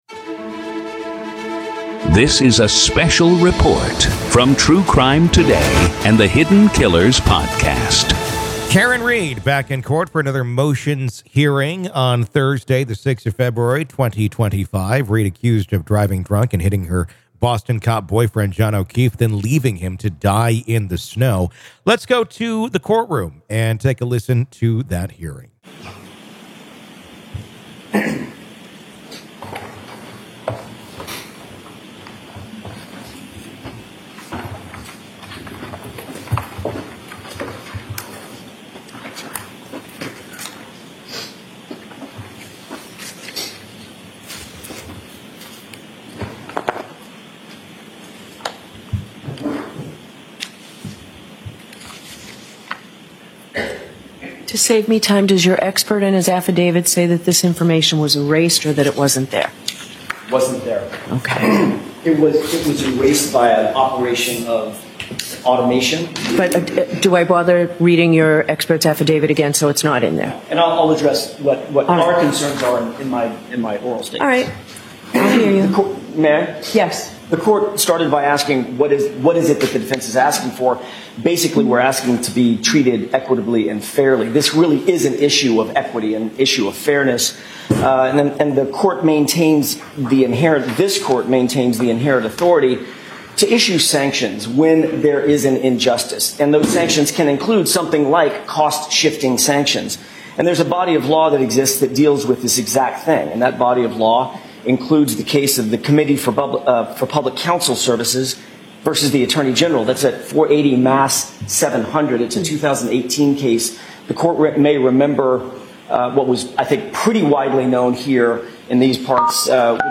RAW COURT AUDIO: PART 1